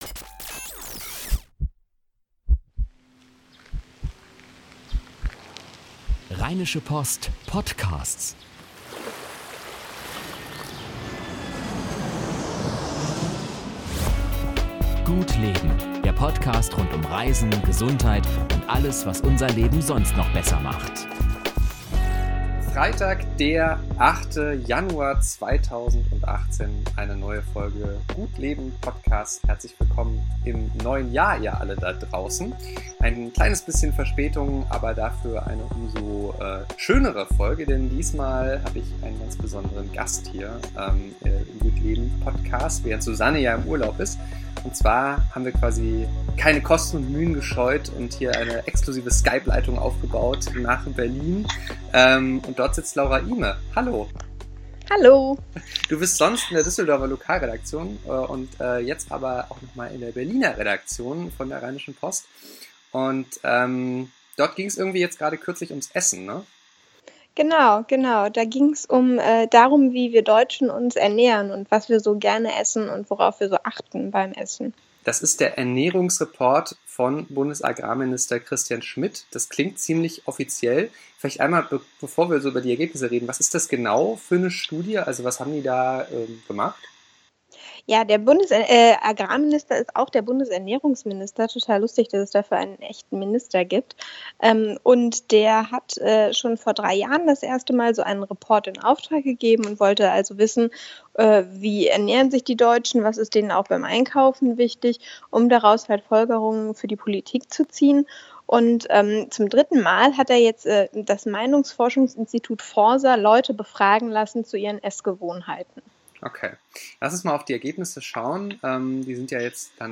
Die Tonprobleme bitten wir zu entschuldigen.